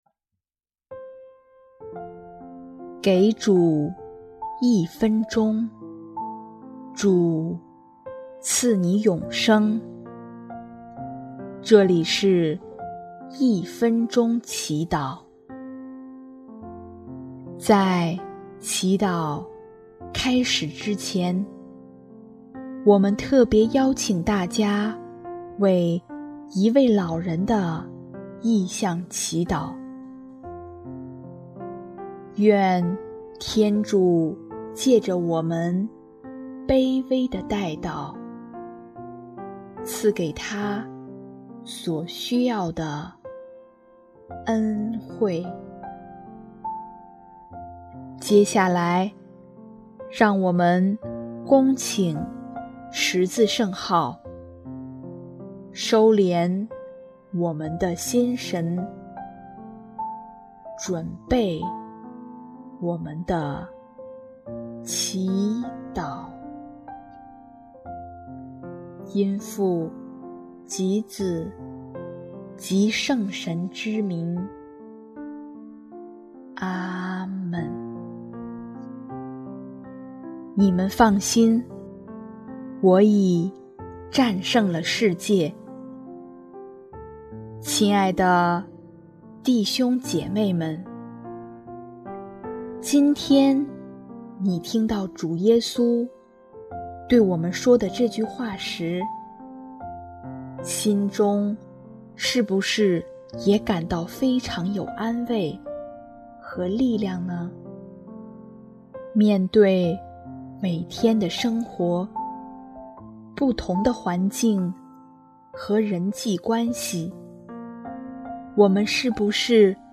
【一分钟祈祷】| 5月22日 力量和希望来源于主耶稣